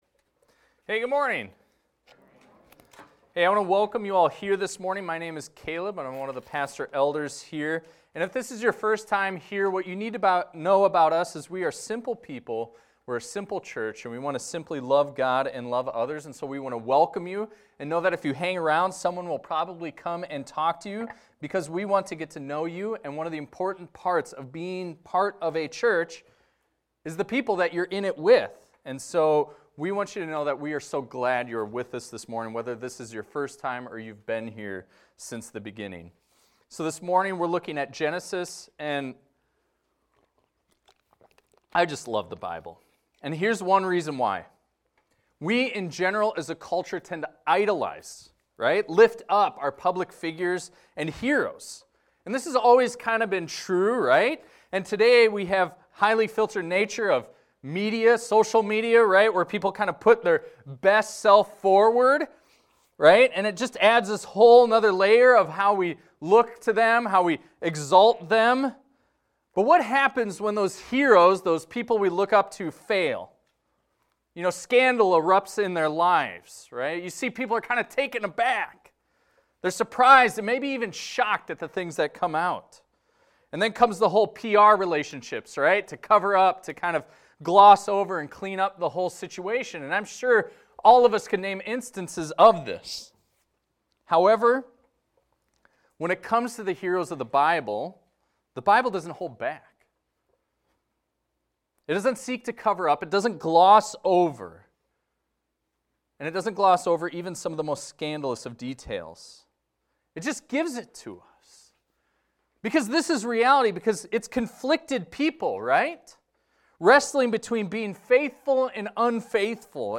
This is a recording of a sermon titled, "The Fear of Abram."